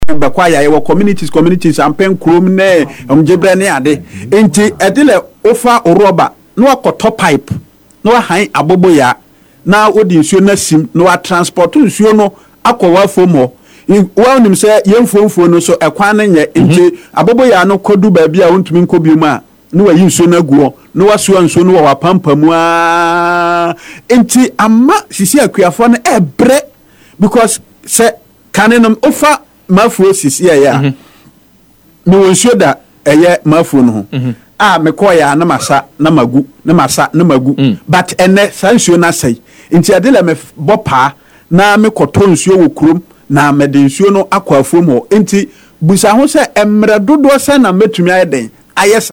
COCOA-FARMERS-LAMENT.mp3